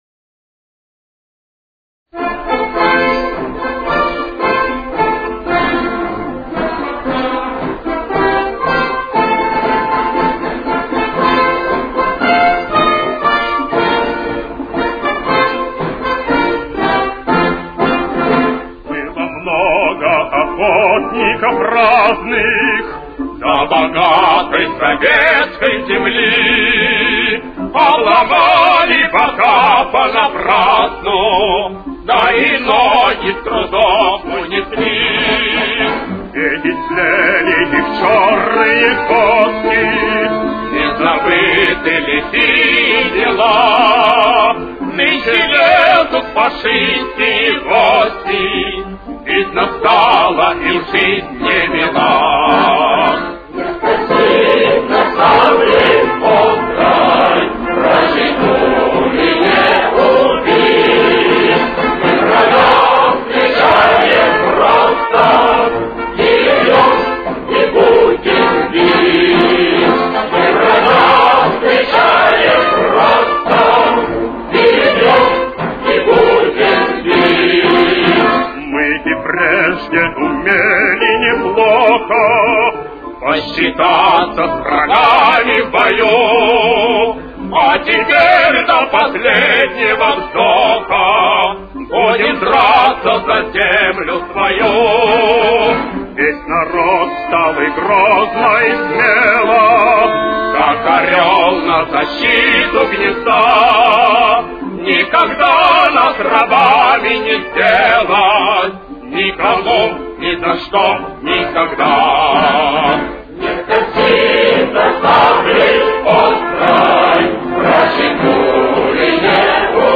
Ре минор. Темп: 114.